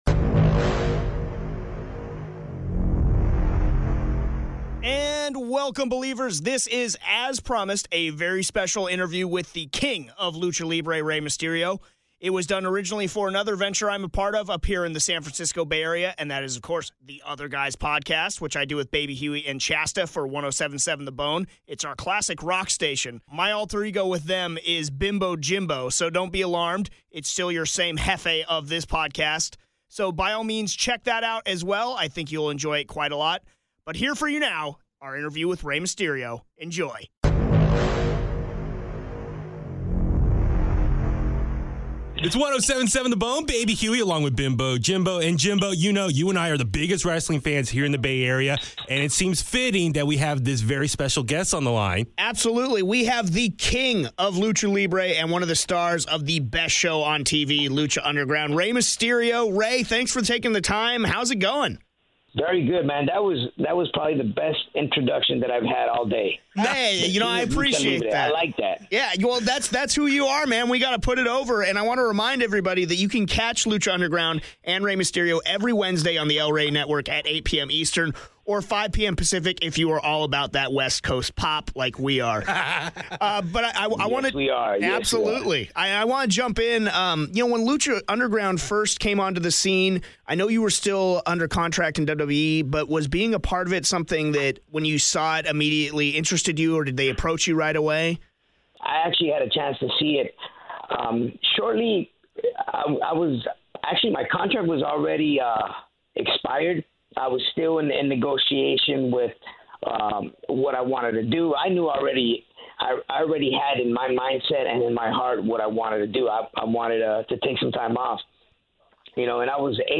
Episode 23: Rey Mysterio Interview